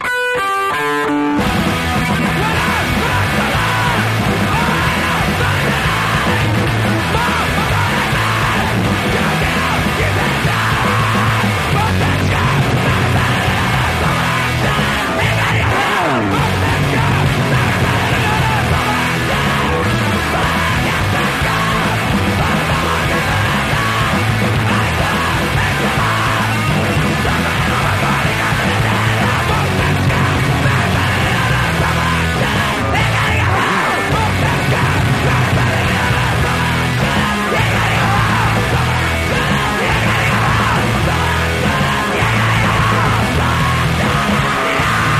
625 THRASHCORE
BASS
DRUMS
GUITAR
VOCALS